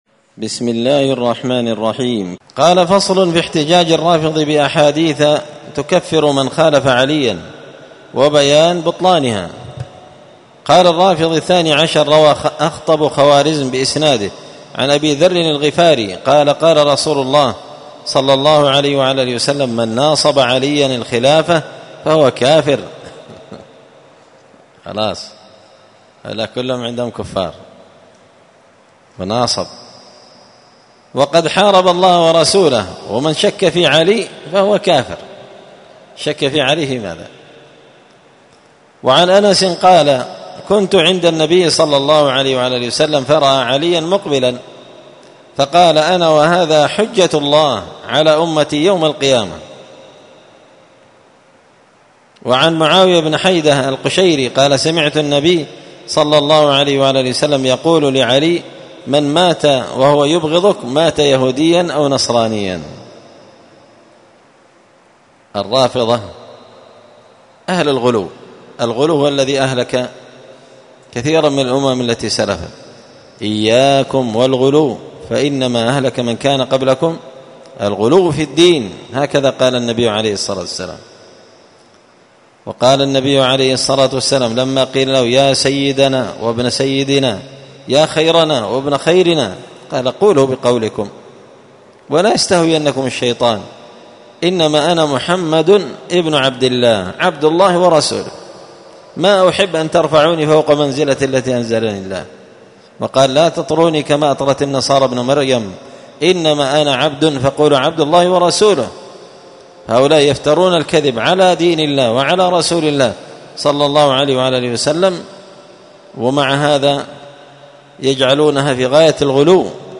الدرس الثاني بعد المائتين (202) فصل في احتجاج الرافضي بأحاديث تكفر من خالف عليا وبيان بطلانها